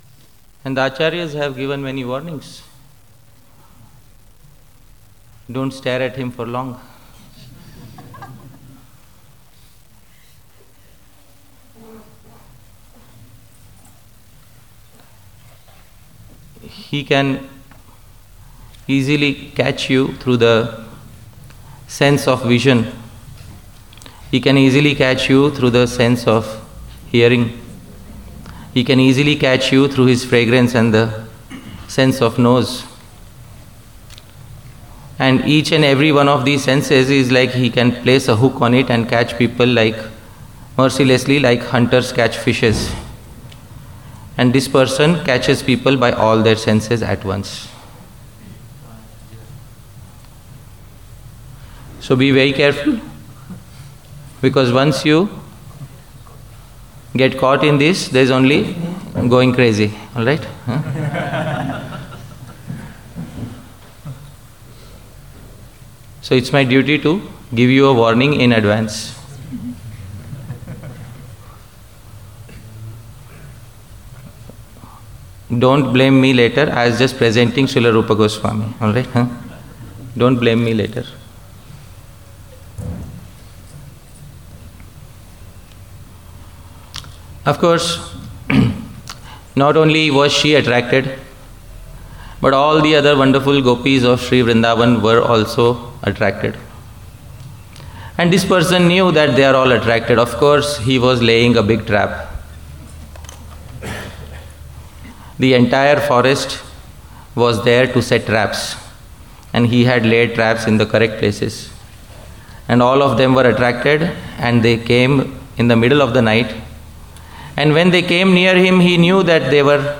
A lecture
Govardhana Retreat Center